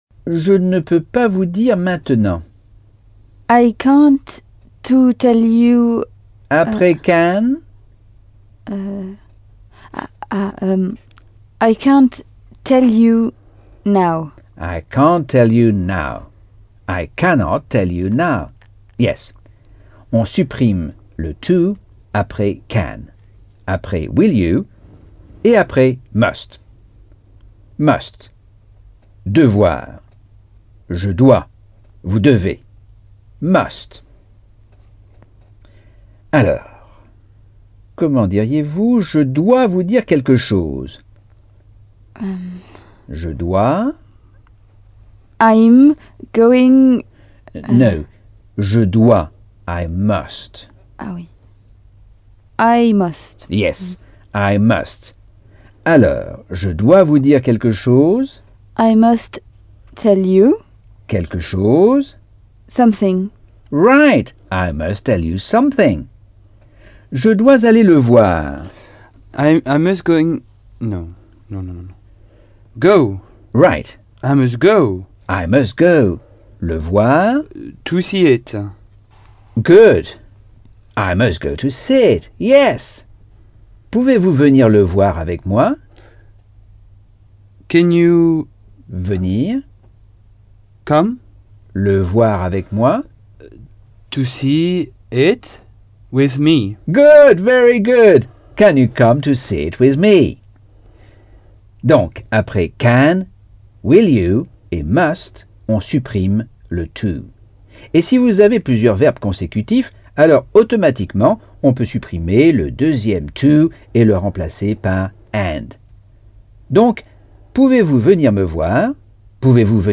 Leçon 2 - Cours audio Anglais par Michel Thomas